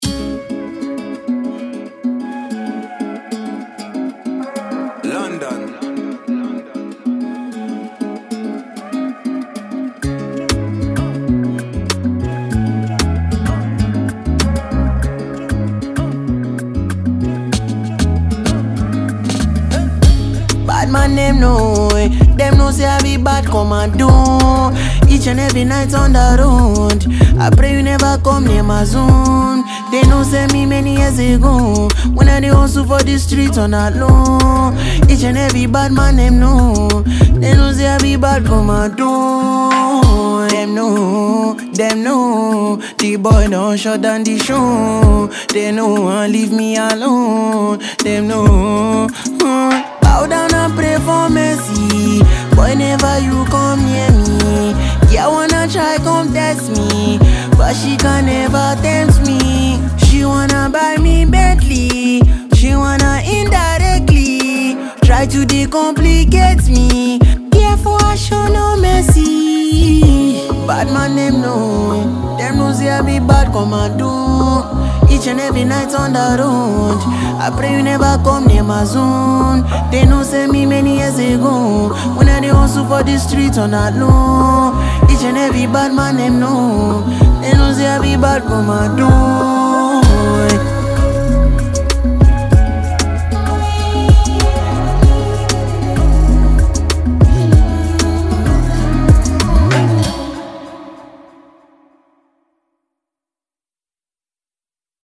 dance single